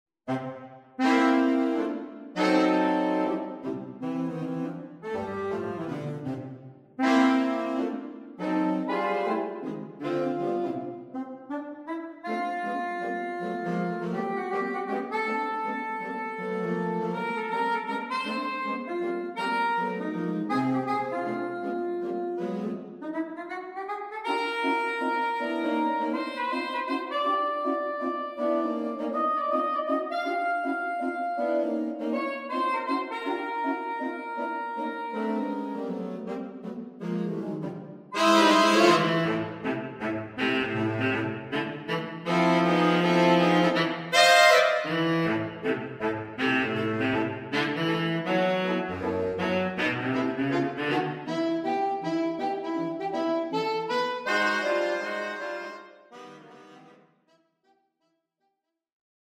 The style of the piece is rooted in ‘big band’ sounds.